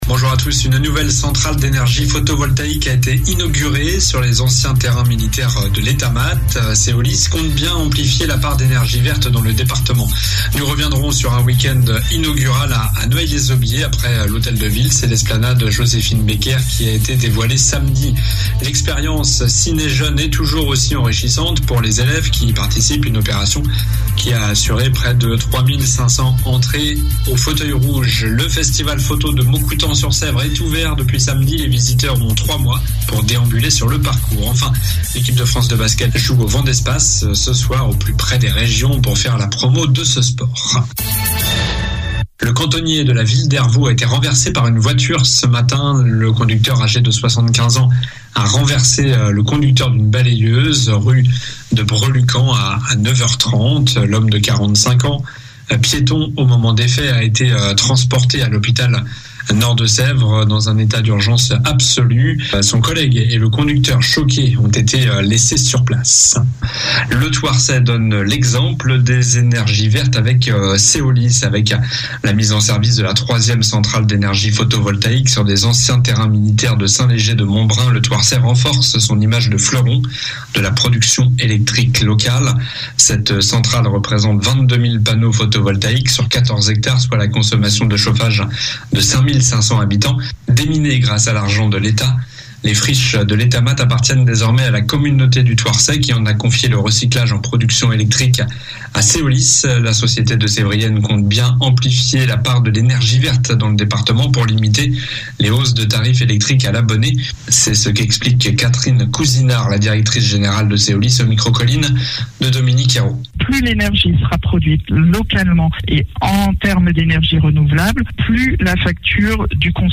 Journal du lundi 4 juillet